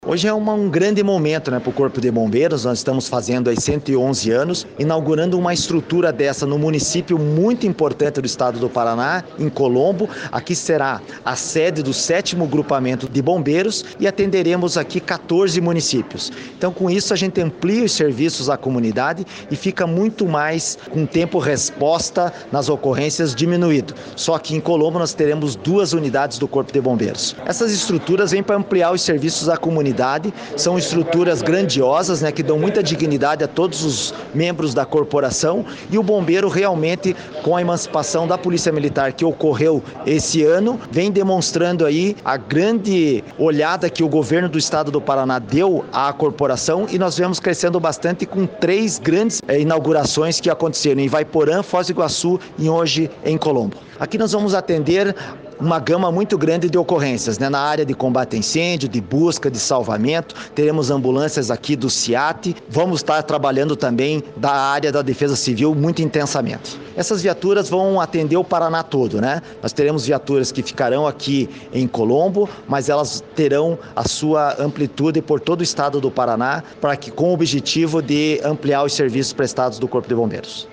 Sonora do comandante-geral do Corpo de Bombeiros Militar do Paraná, coronel Manoel Vasco de Figueiredo Junior, sobre a inauguração do segundo maior quartel do Corpo de Bombeiros, em Colombo
MANOEL VASCO - INAUGURACAO BOMBEIROS COLOMBO.mp3